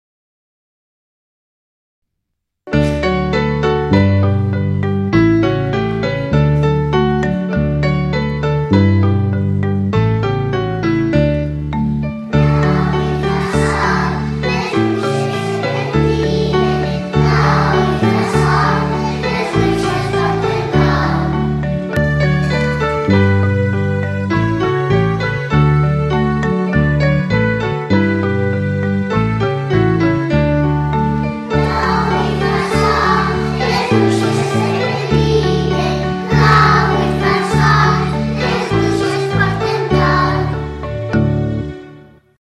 Us deixo penjat al bloc un nou episodi del CD de Cançons Populars que hem enregistrat a l’escola.
Els alumnes de P4 canten “Plou i fa sol” i pentinen les bruixes els dies que plou.